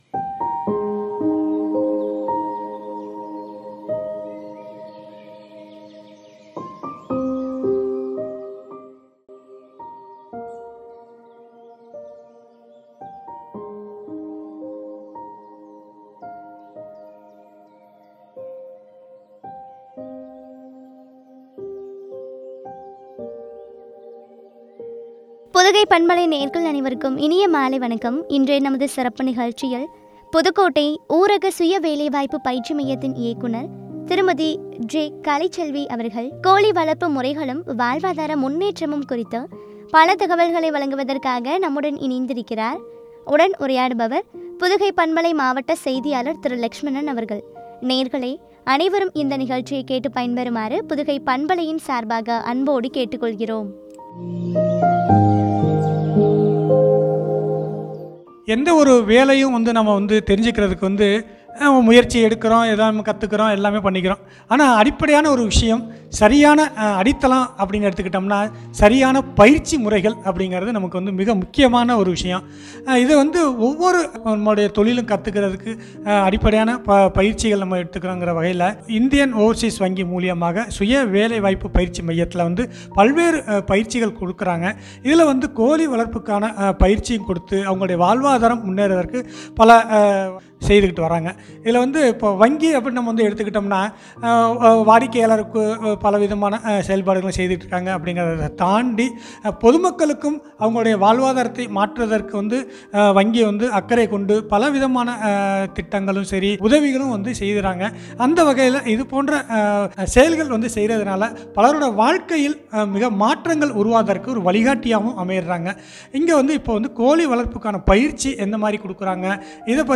கோழி வளர்ப்பு முறைகளும், வாழ்வாதாரம் முன்னேற்றமும் குறித்த உரையாடல்.